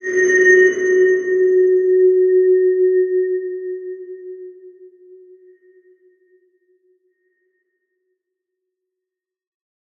X_BasicBells-F#2-mf.wav